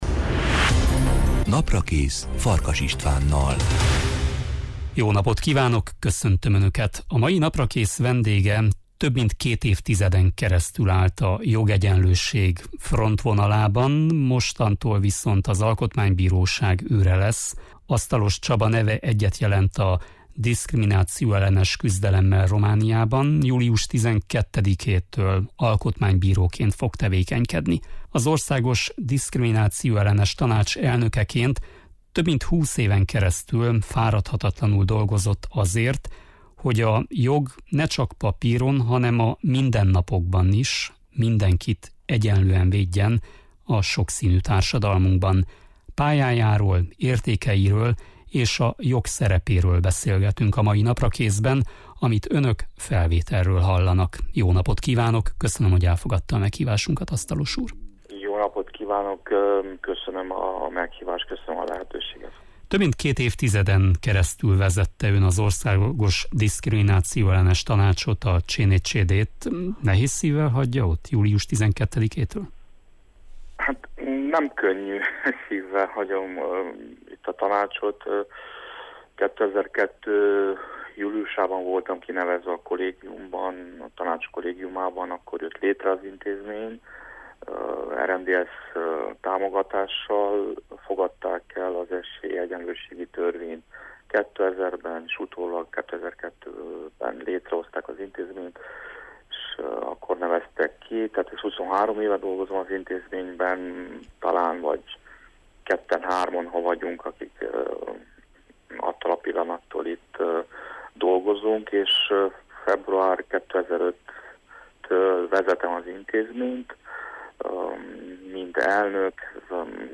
Az egyenlőség arcai. Beszélgetés Asztalos Csabával